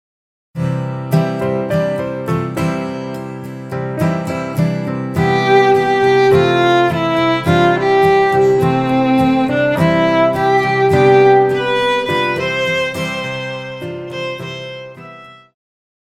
Pop
Viola
Band
Instrumental
Rock,Country
Only backing